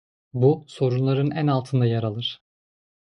Pronounced as (IPA) /æn/